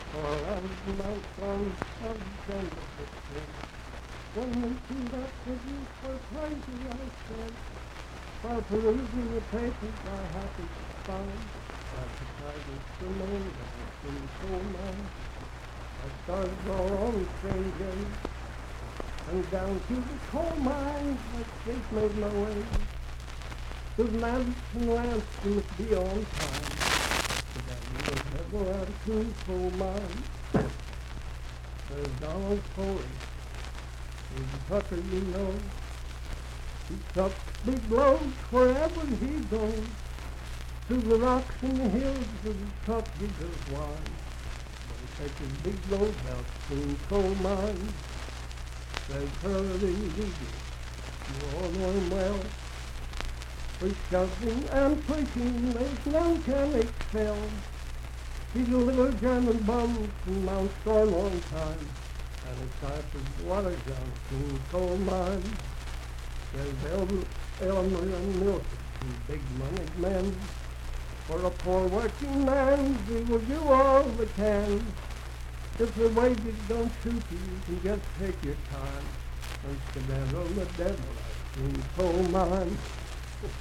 Unaccompanied vocal music
in Mount Storm, W.V.
Verse-refrain 4(4).
Voice (sung)